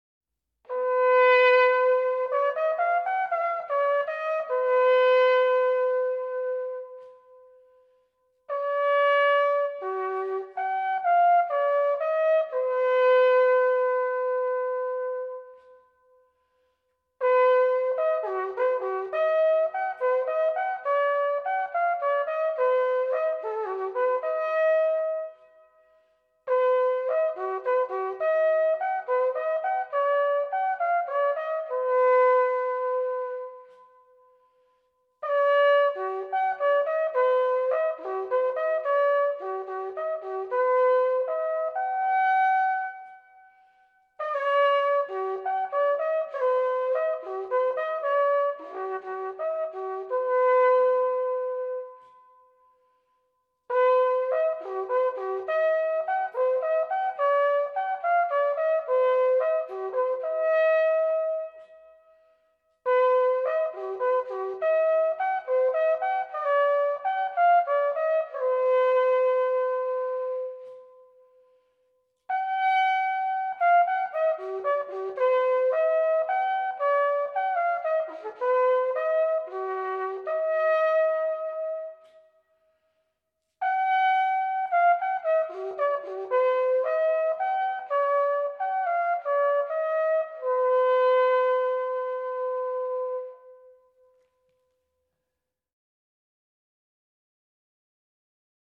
A Swiss National Yodeling Festival
Büchel solo perfromance